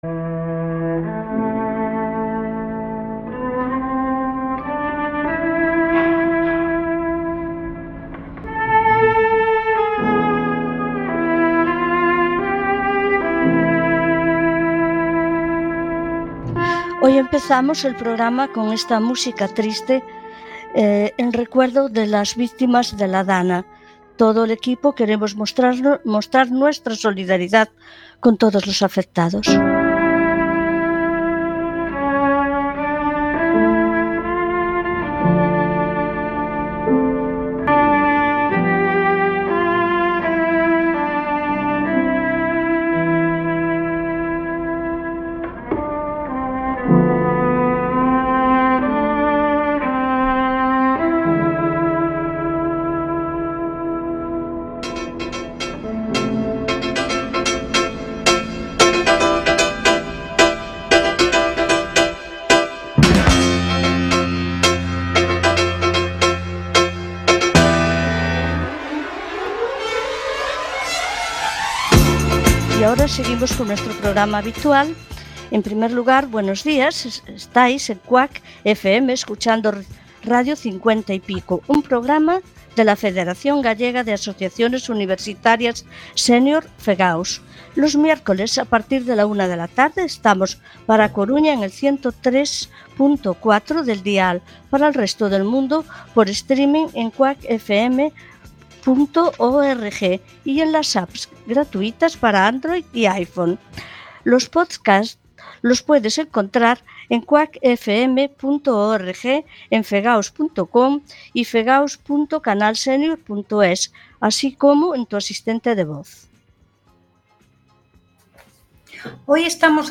Radio 50 y Pico es un proyecto de comunicación de la Federación Gallega de Asociaciones Universitarias Senior (FEGAUS). Se realiza desde cinco de los siete campus universitarios de Galicia y aborda todo tipo de contenidos de interés, informativos, culturales, de actualidad y de entretenimiento.